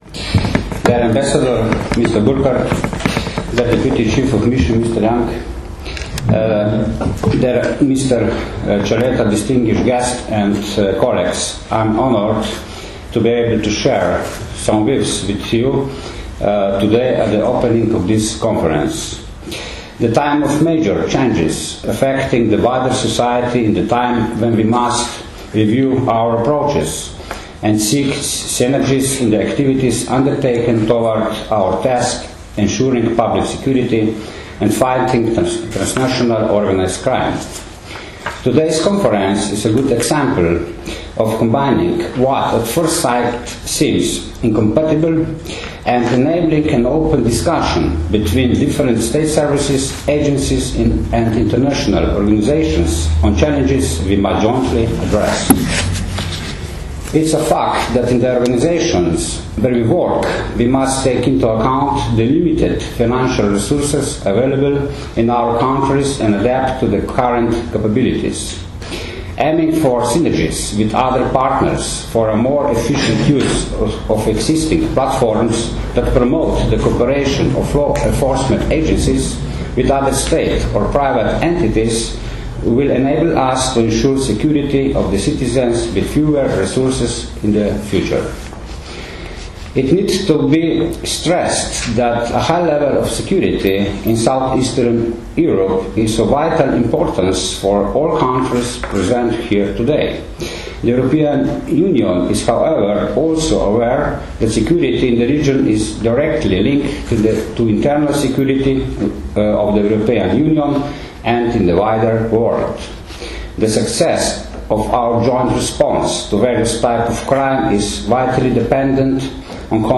Z uvodnimi prispevki so udeležence konference nagovorili nemški veleposlanik v Sloveniji Werner Burkart, namestnik ameriškega veleposlanika v Sloveniji Eugene Young, generalni direktor policije Stanislav Veniger in drugi visoki gostje.
Zvočni posnetek nagovora Stanislava Venigerja, generalnega direktorja policije (v angleščini) (mp3)